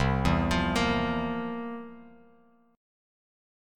Listen to C11 strummed